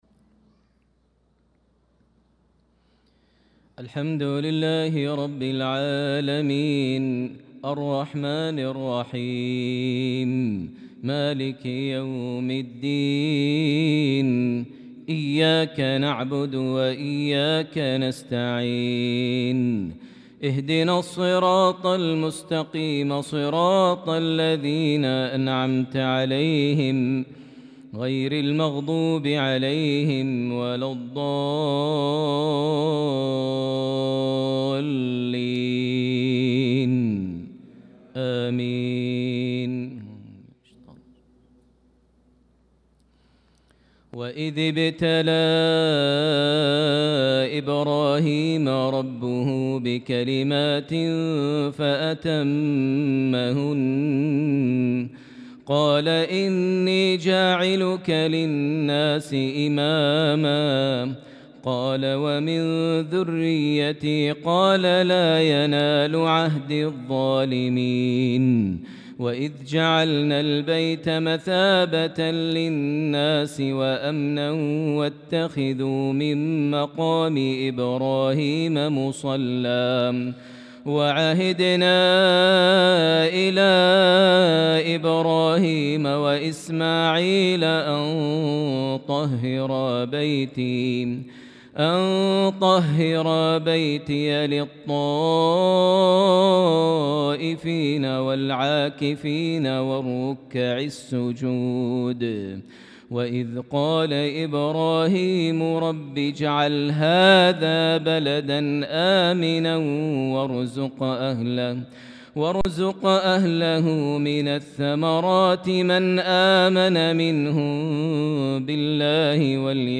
صلاة العشاء للقارئ ماهر المعيقلي 19 شوال 1445 هـ